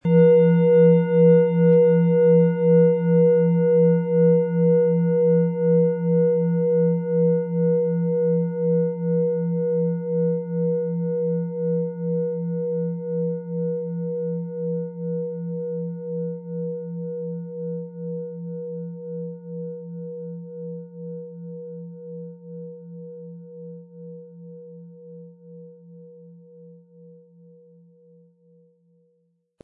Von Hand getriebene Klangschale mit dem Planetenklang Chiron aus einer kleinen traditionellen Manufaktur.
• Mittlerer Ton: Mond
MaterialBronze